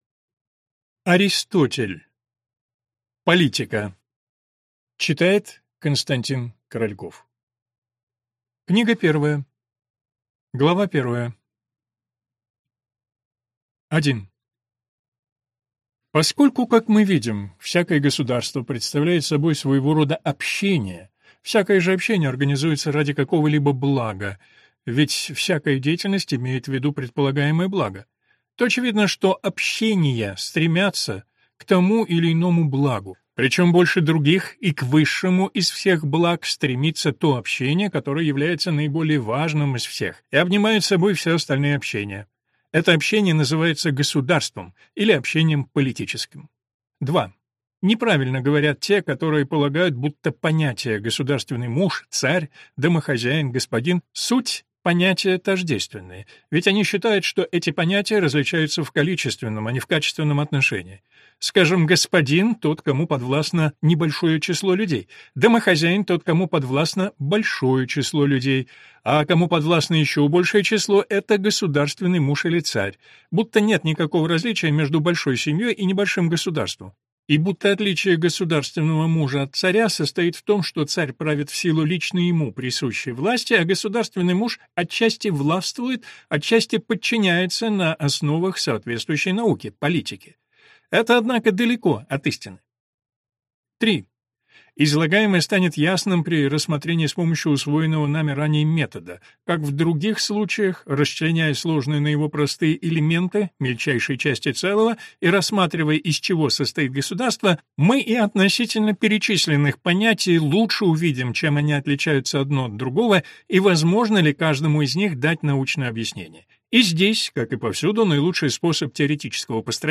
Аудиокнига Политика | Библиотека аудиокниг